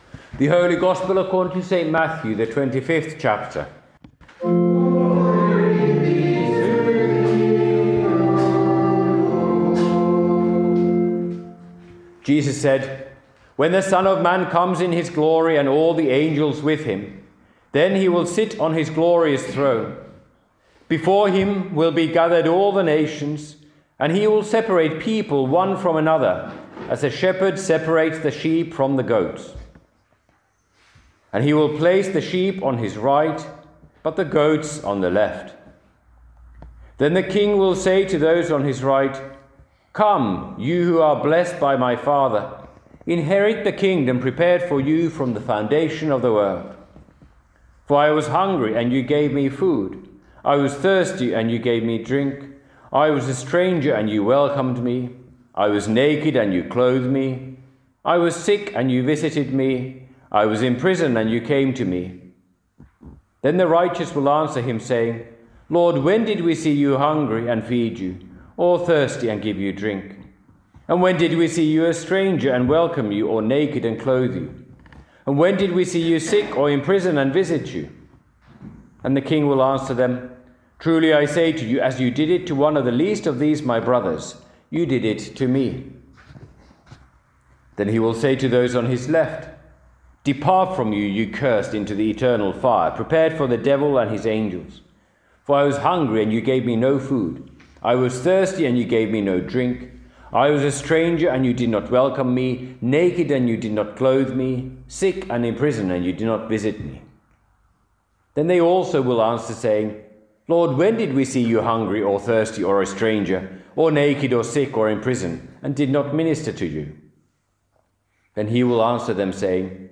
by admin | Nov 16, 2025 | Sermons, Trinity, Trinity 26